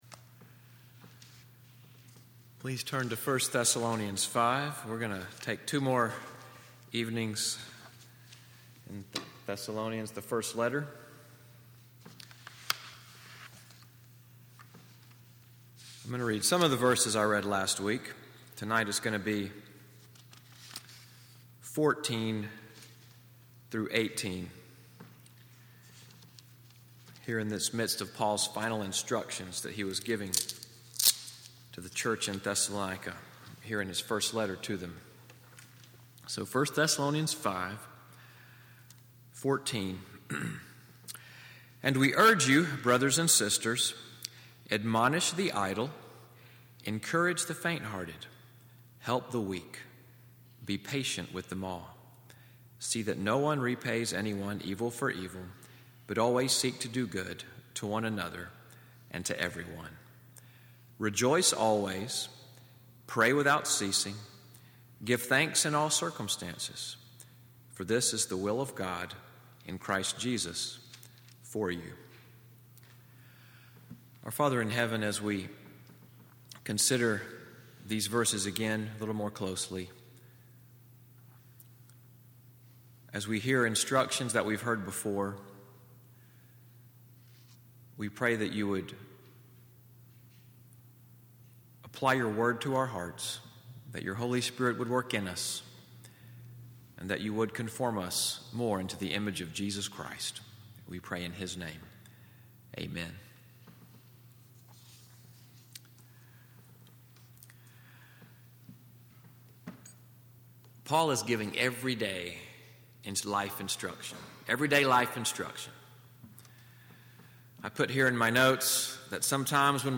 Evening Worship at NCPC-Selma, audio from the sermon, “Spiritual Dynamics,” January 21, 2018.